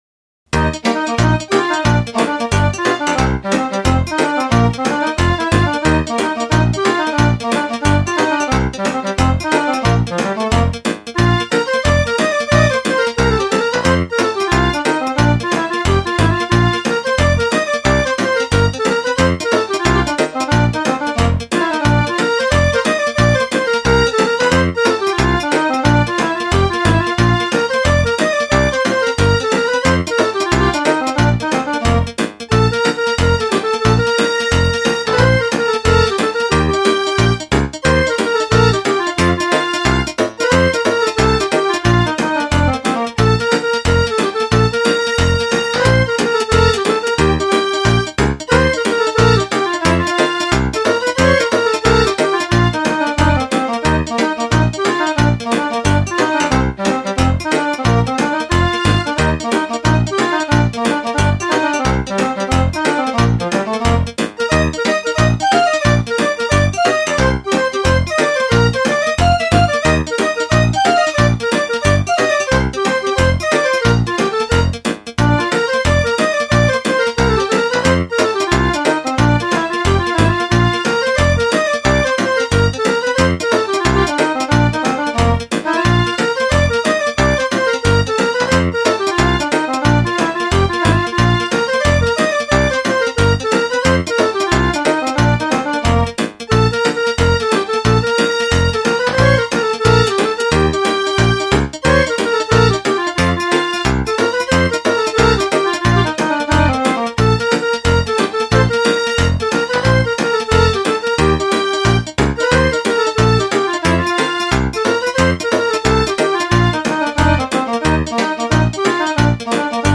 Hora din pojorata - danse (mp3)